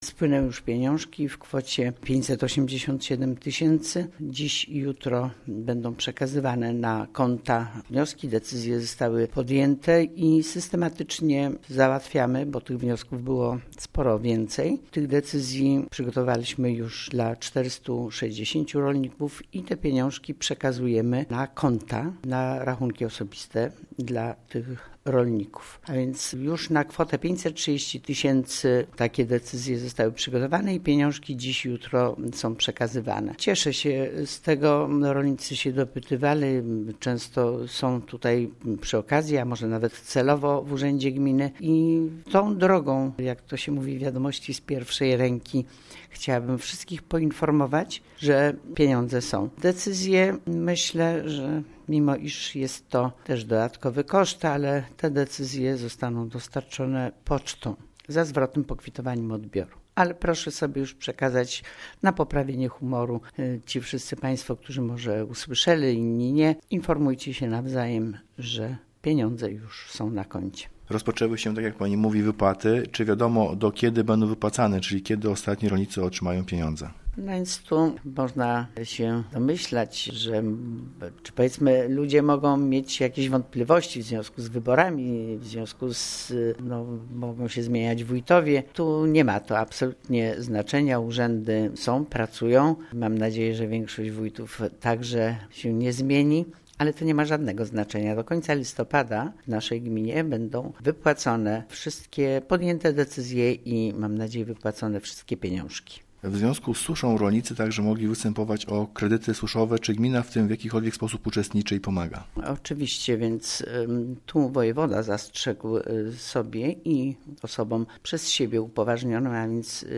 Jeszcze w tym tygodniu w ramach tego programu do rolników z Gminy Łuków trafią pierwsze pieniądze. O szczegółach informuje:
Wójt Gminy Łuków